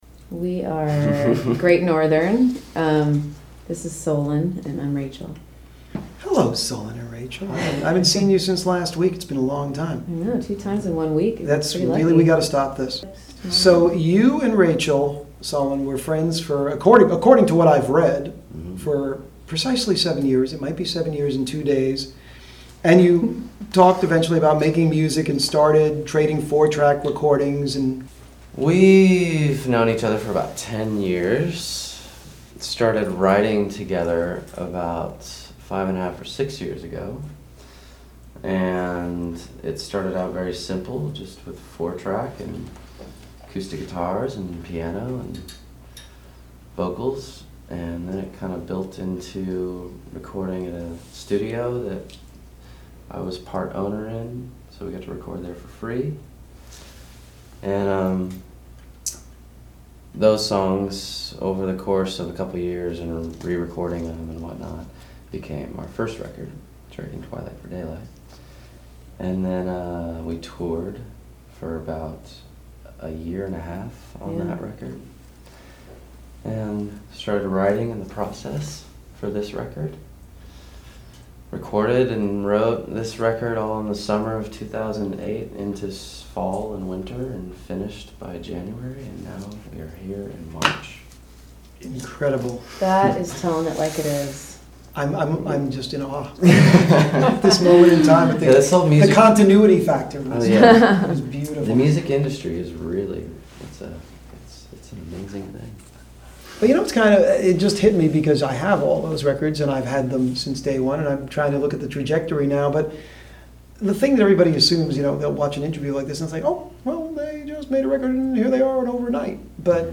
This Week's Interview (05/24/2009):